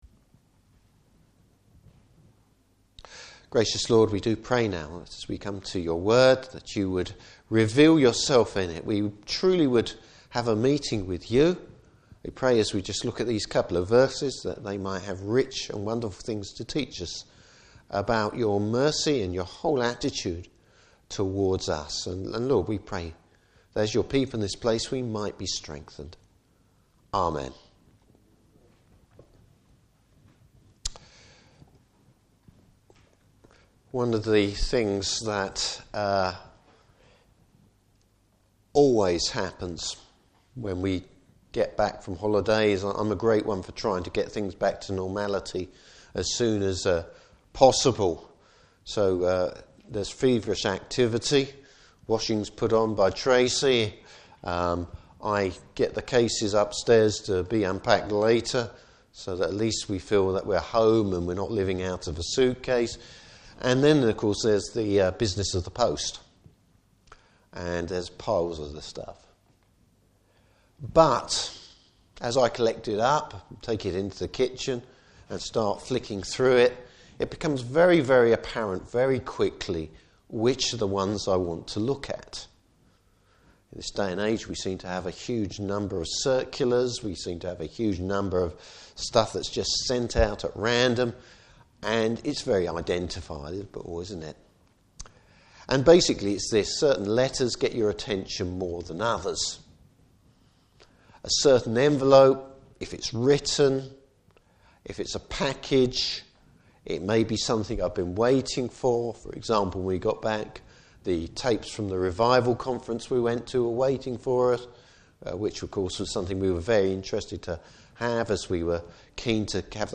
Service Type: Morning Service Bible Text: Ephesians 1:1-2.